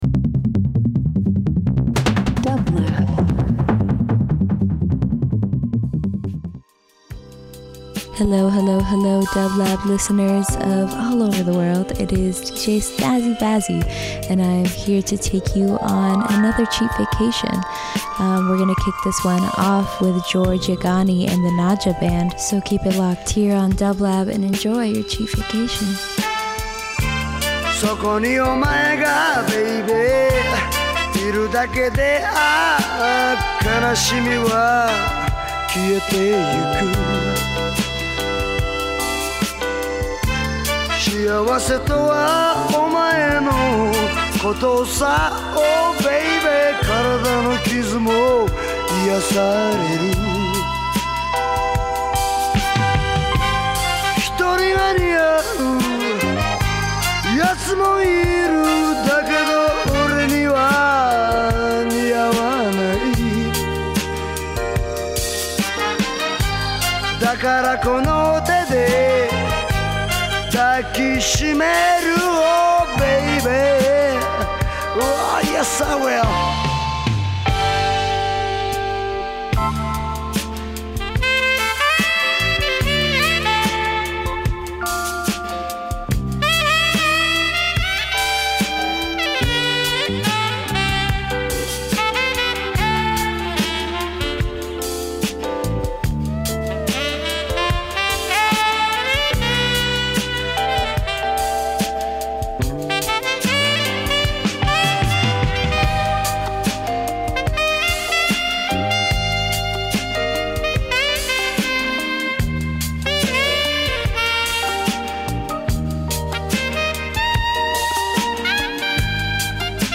Funk/Soul Hip Hop R&B